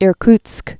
(îr-ktsk)